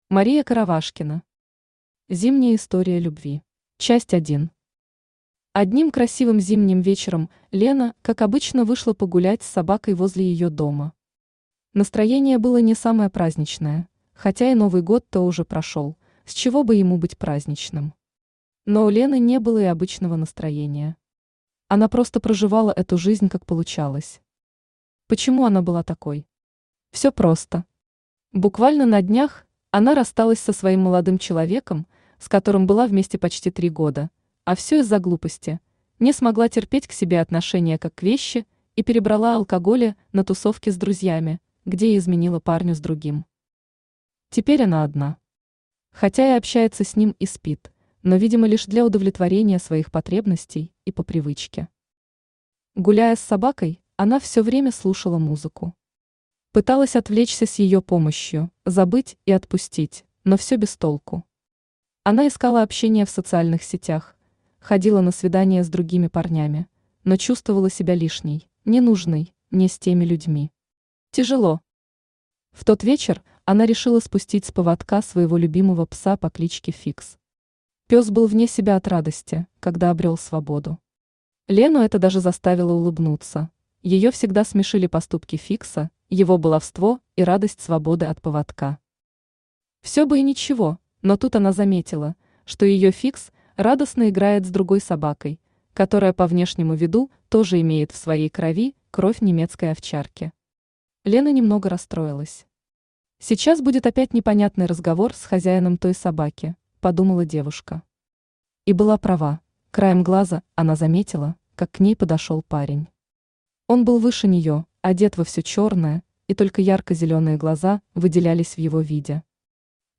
Aудиокнига Зимняя история любви Автор Мария Сергеевна Коровашкина Читает аудиокнигу Авточтец ЛитРес.